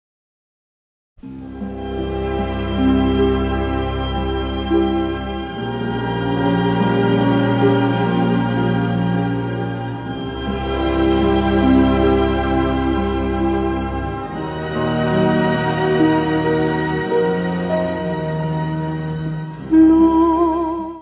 piano
: stereo; 12 cm + folheto
Área:  Música Clássica